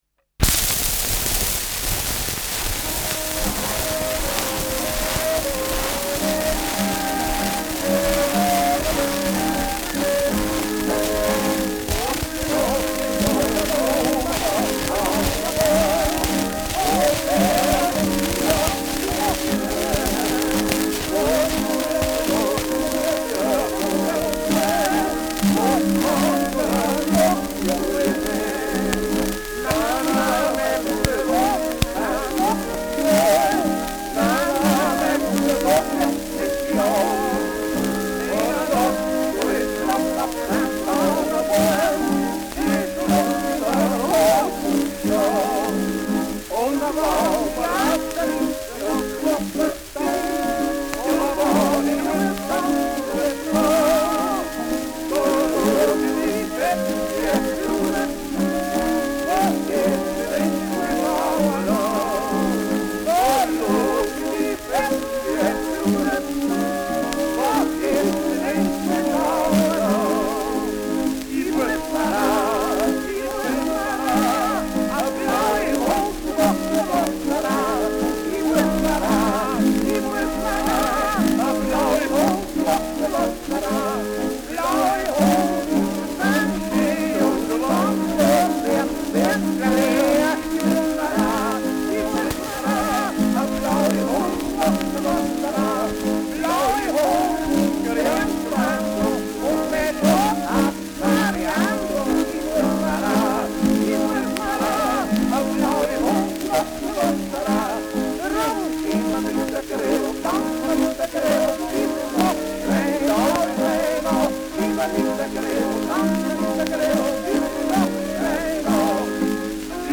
Schellackplatte
Tonnadel springt zu Beginn : präsentes bis starkes Rauschen : leiert : präsentes Knistern : gelegentliches Knacken : gelegentliches „Schnarren“ : abgespielt
Strohmayer-Quartett (Interpretation)
Potpourri mit bekannten Volksliedern, Vierzeilern und Tanzliedern.